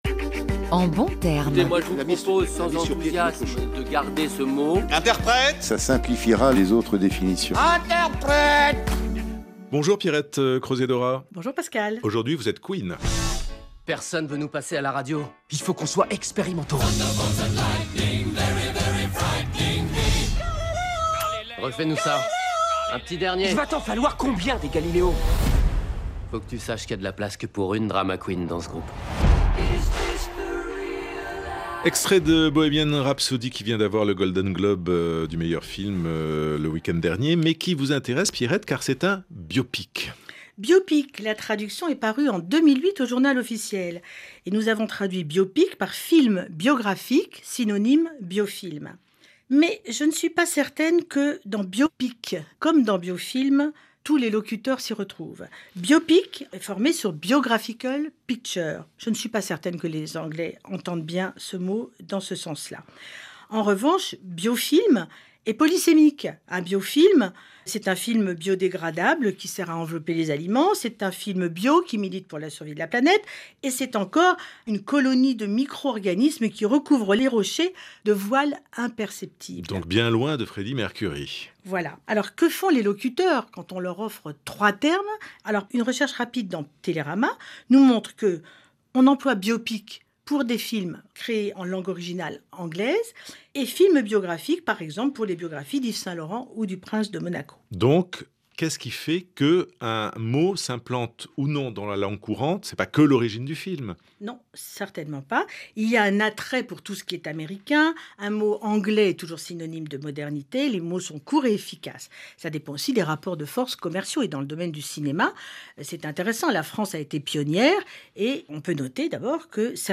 La DGLFLF lance « En bons termes », une chronique radiophonique mensuelle